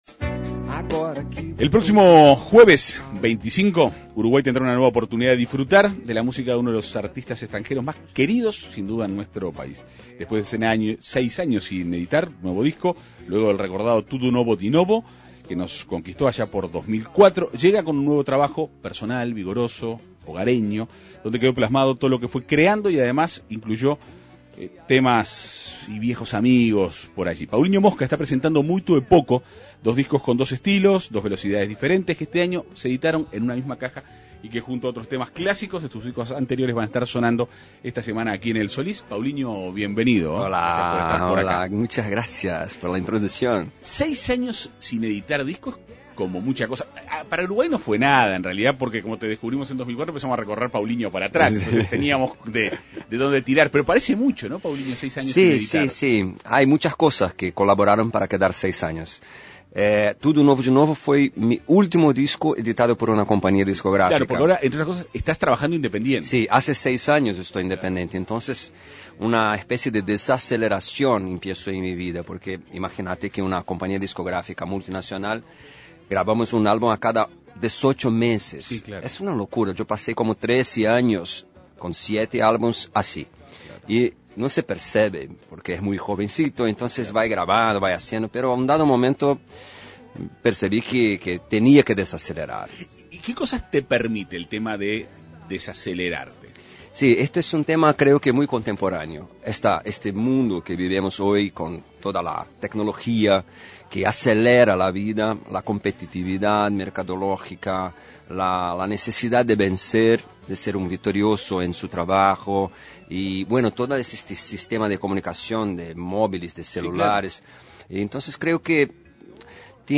Paulinho Moska presenta Muito e Pouco, en el Teatro Solís. El músico dialogó en la Segunda Mañana de En Perspectiva.